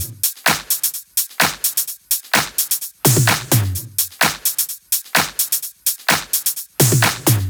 VFH2 128BPM Unimatrix Kit 5.wav